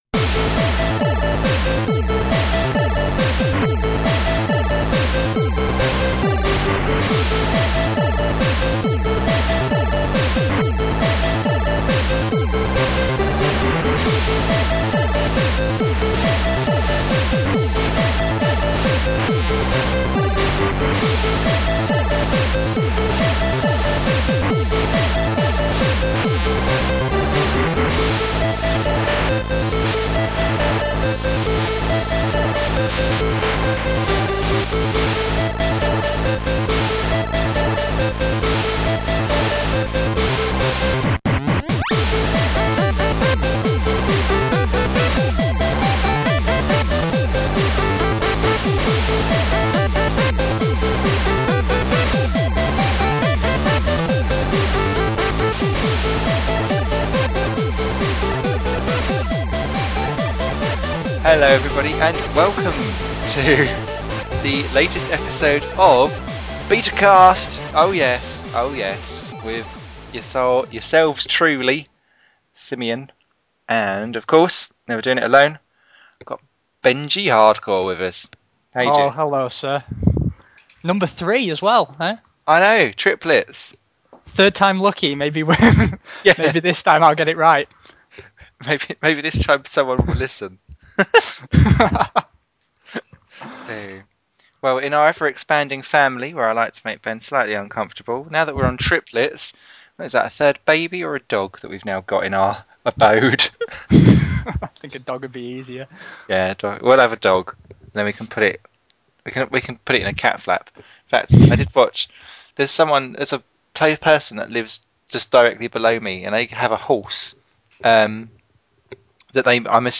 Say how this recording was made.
(Apologies for a couple of sound problems – this was due to a connection issue)